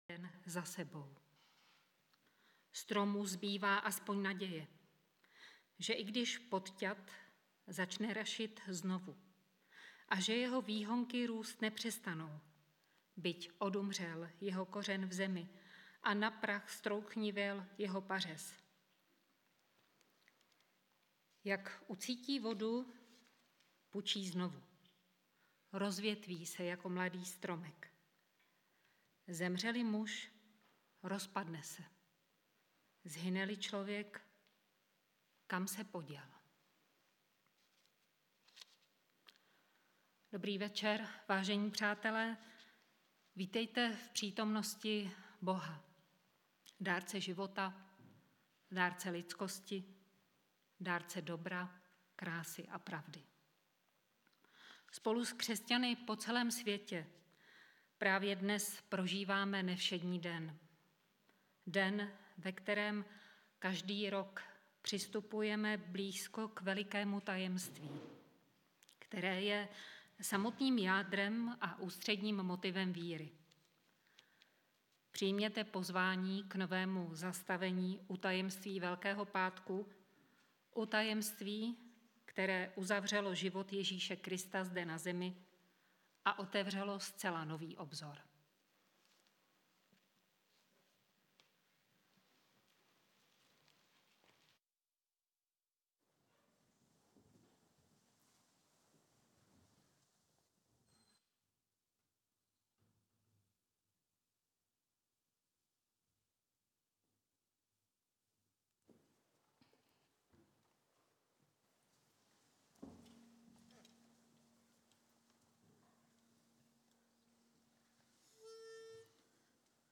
Velkopáteční bohoslužba